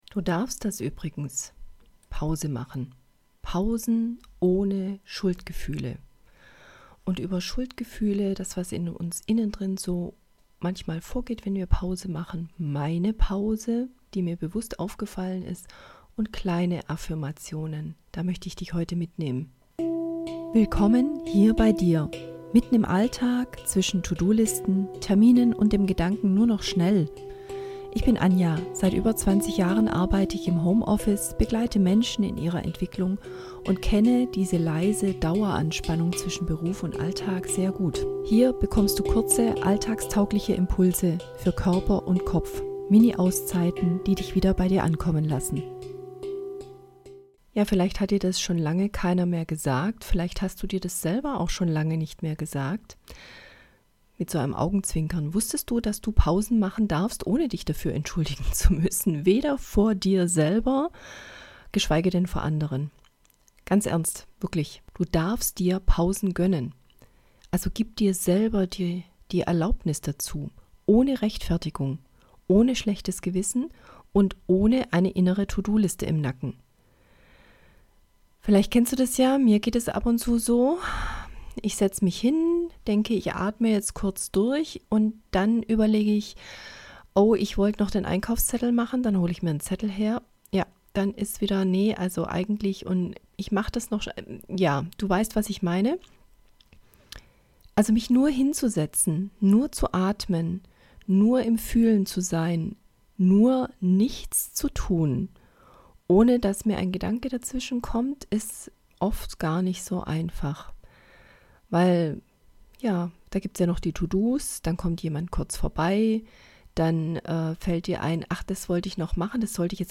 Eine kleine Minioasen in deinem Tag, mit stärkenden Affirmationen, die dein inneres "Ich darf das" wieder freilegen.